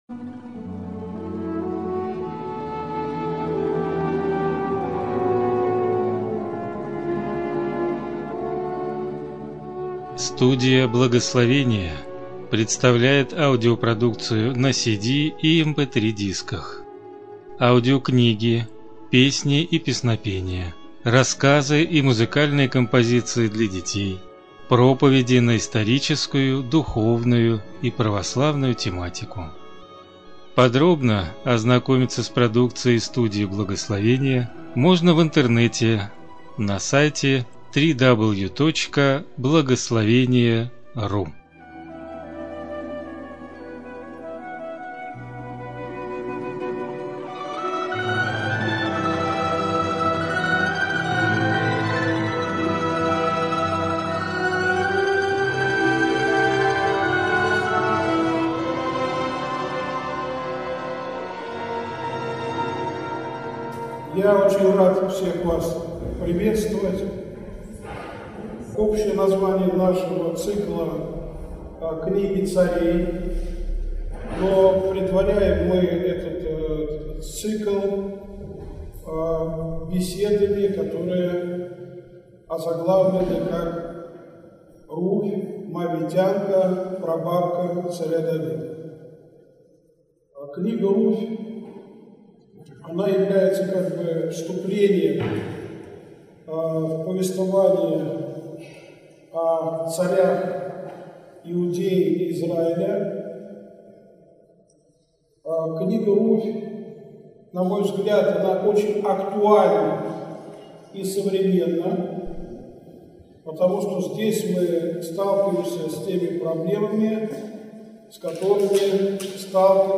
Аудиокнига Руфь Моавитянка | Библиотека аудиокниг